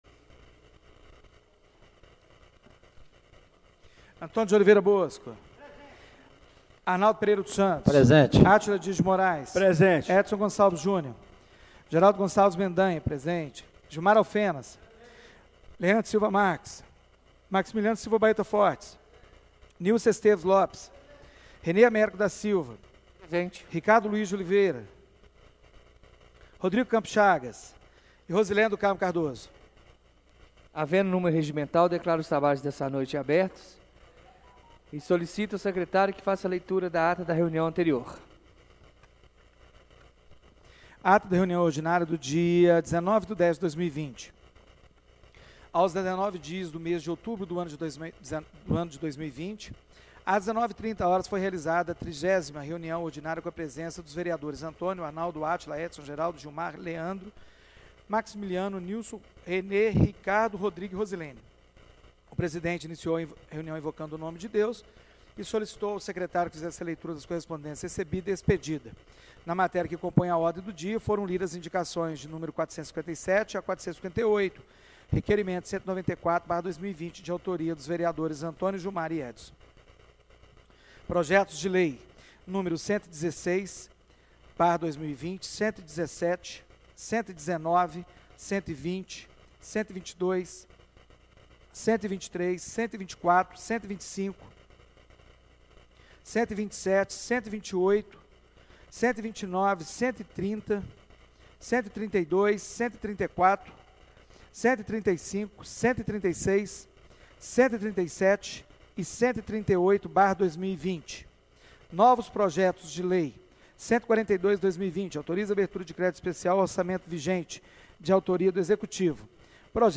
Reunião Ordinária do dia 26/10/2020